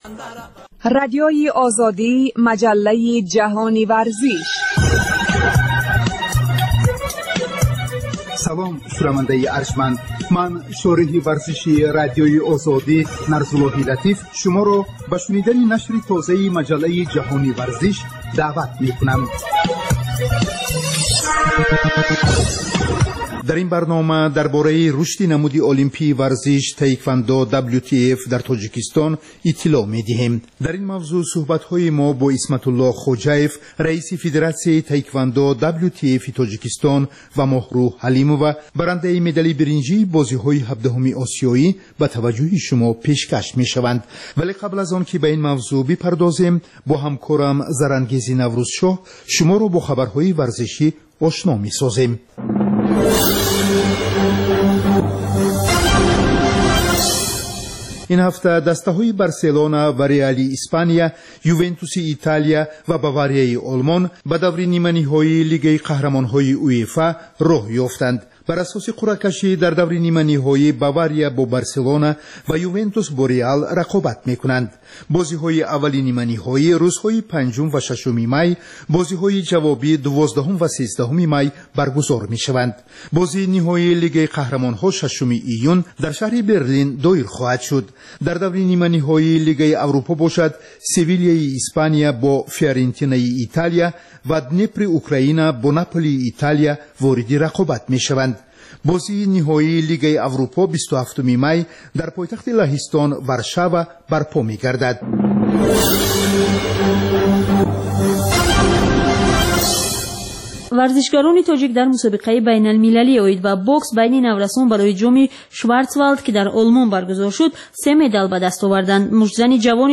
Варзиш дар Тоҷикистон ва ҷаҳон. Маҷаллаи вижаи дӯстдорони ахбор ва гузоришҳои варзишии Радиои Озодӣ.